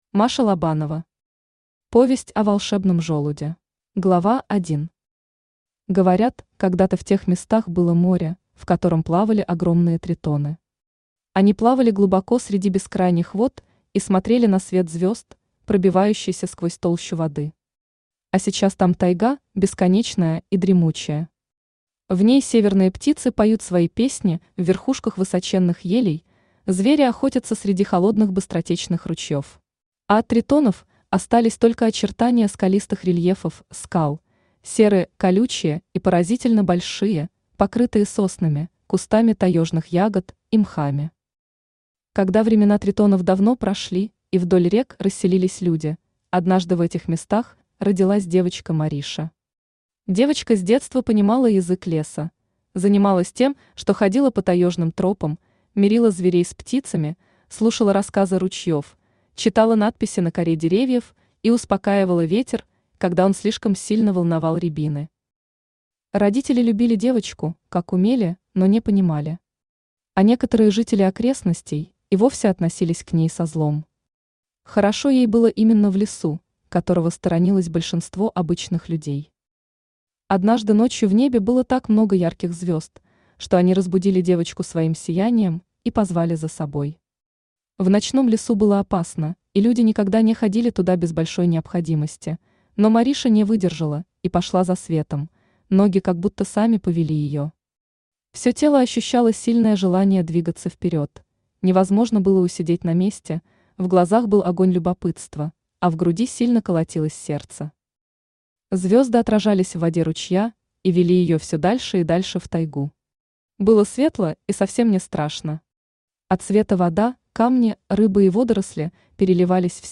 Аудиокнига Повесть о волшебном желуде | Библиотека аудиокниг
Aудиокнига Повесть о волшебном желуде Автор Маша Лобанова Читает аудиокнигу Авточтец ЛитРес.